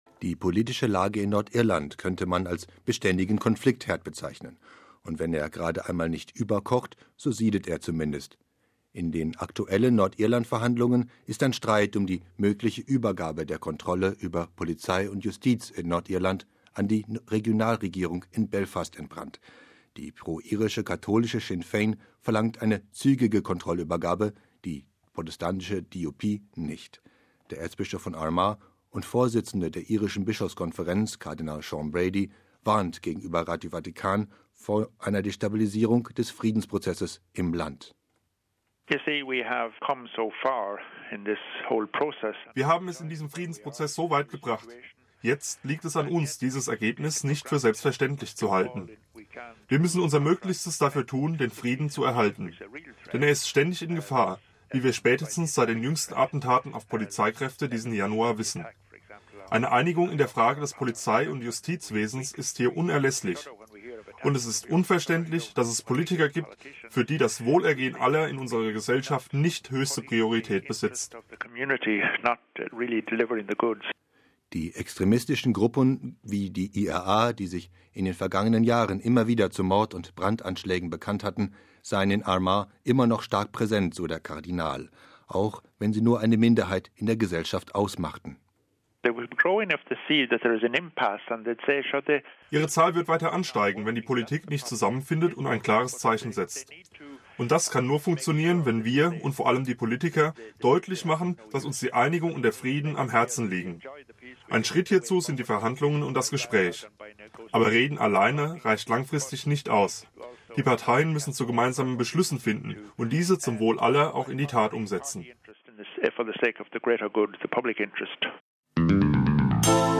Der Erzbischof von Armagh und Vorsitzende der Irischen Bischofskonferenz, Kardinal Sean Brady, warnt gegenüber Radio Vatikan vor einer Destabilisierung des Friedensprozesses im Land: